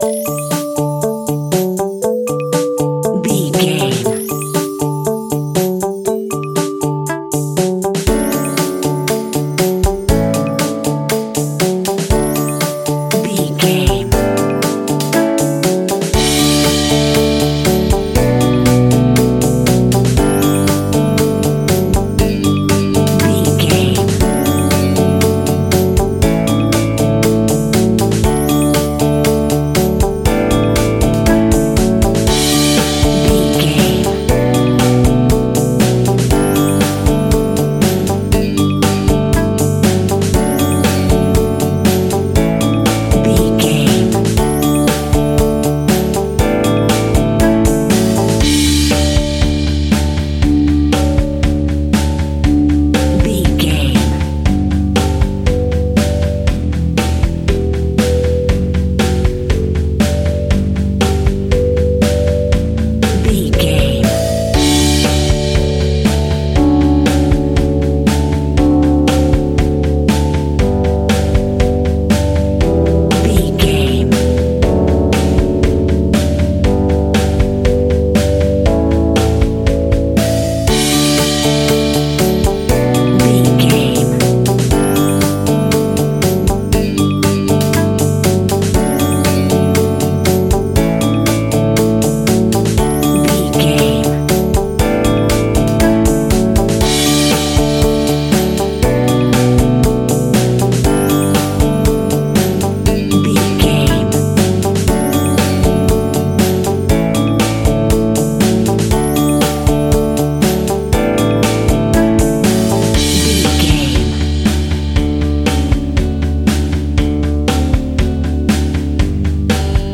Ionian/Major
D
indie pop
pop rock
sunshine pop music
drums
bass guitar
electric guitar
piano
hammond organ